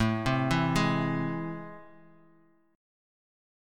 AmM7 chord